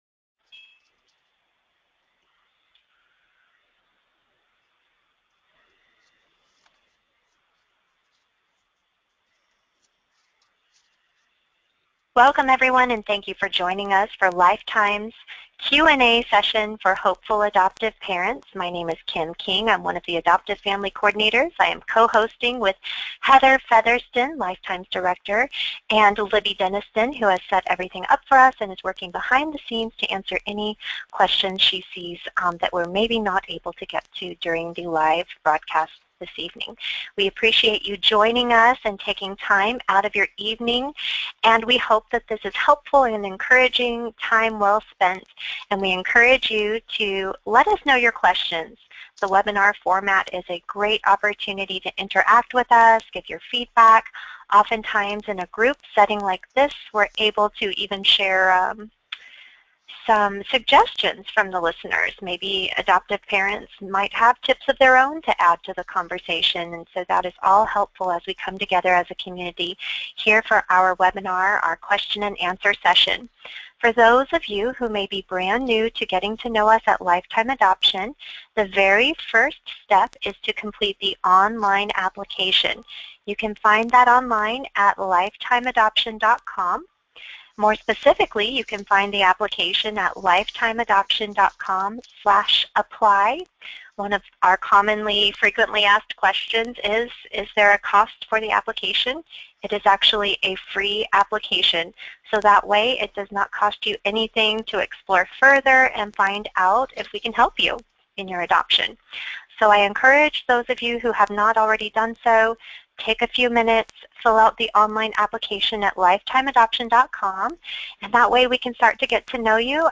experts discuss what you need to know for success, application process, what birth mothers are seeking in adoptive parents, and more